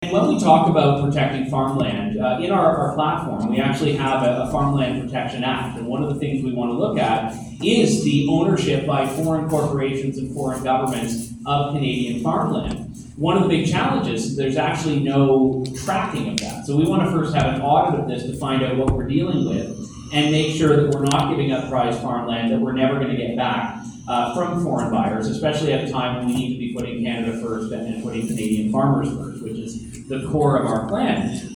The final all-candidate debate/meet and greet was held Wednesday at the Keystone Complex in Shedden with all four candidates participating, fielding pre-selected, ag-related questions.